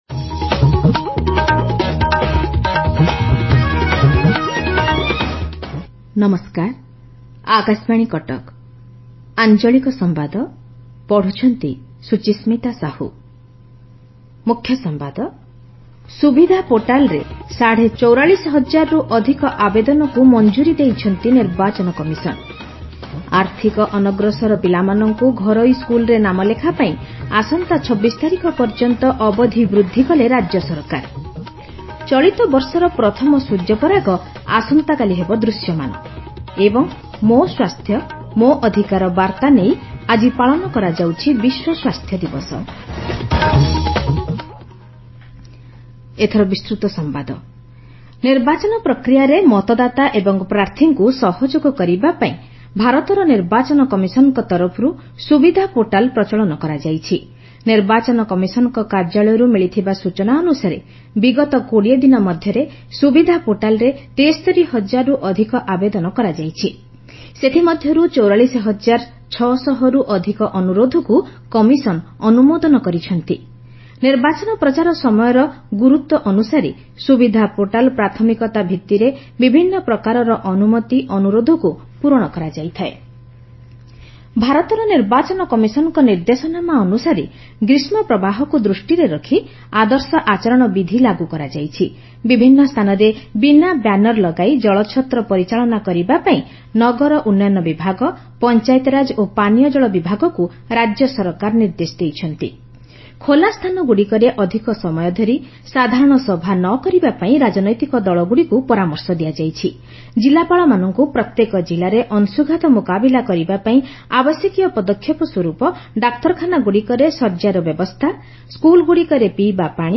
Transcript summary Play Audio Midday News